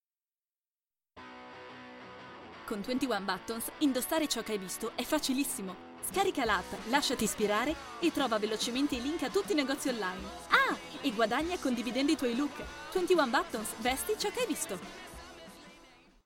Italian professional voice over, bright, energetic, friendly and dynamic.
Sprechprobe: Werbung (Muttersprache):